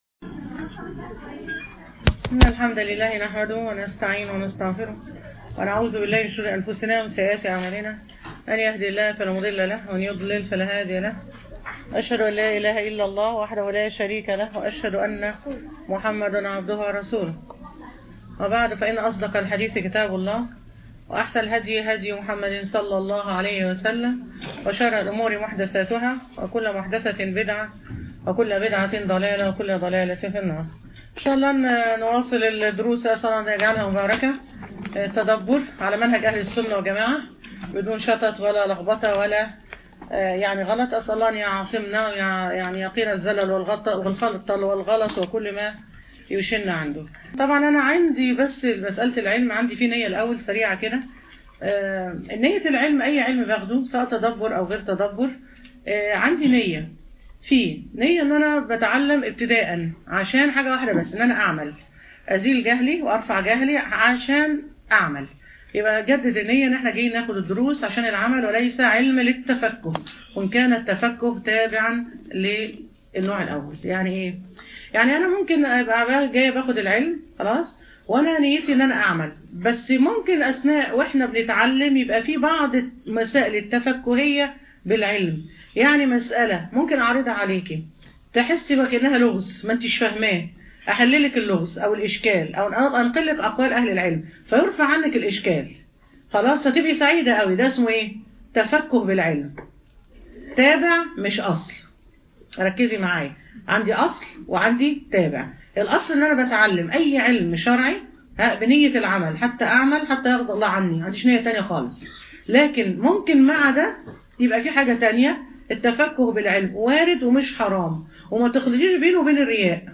تدبر-سورة-البقرة-المحاضرة-الثانية-من-آية “5-13”